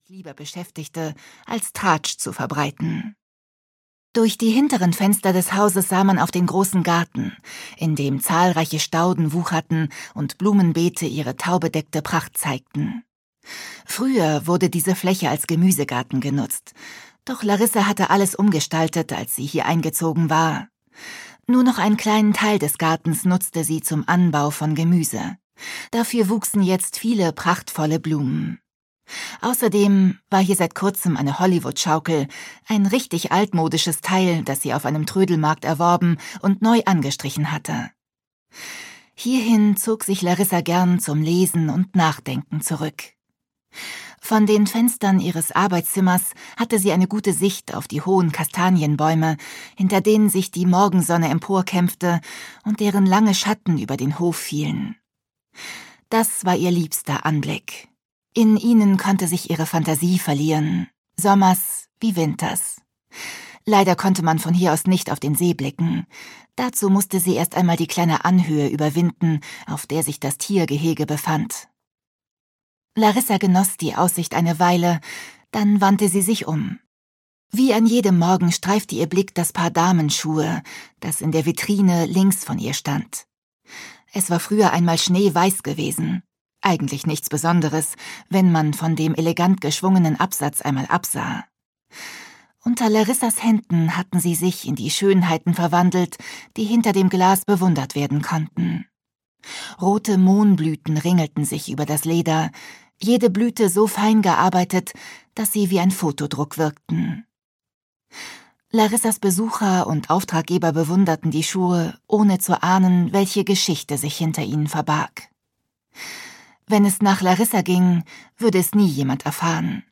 Hörbuch Ein zauberhafter Sommer, Corina Bomann.